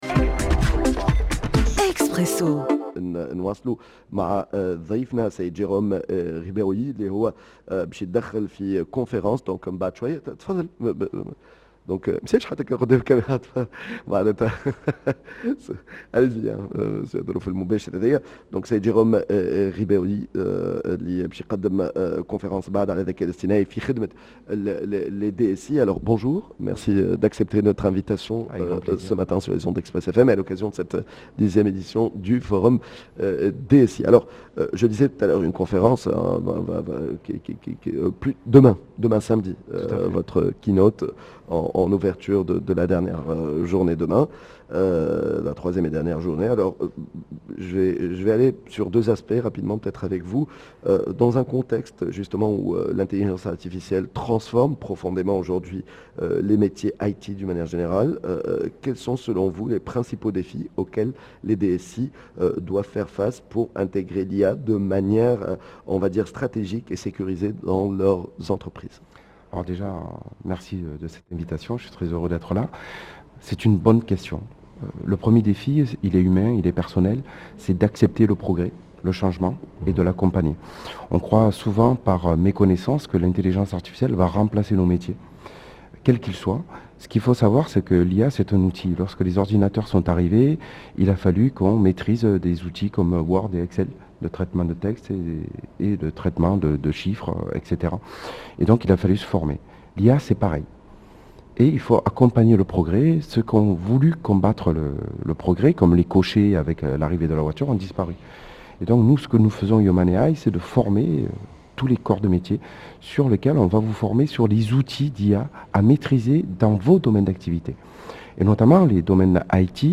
What Next?» dans un plateau spécial en direct de Yasmine El Hammamet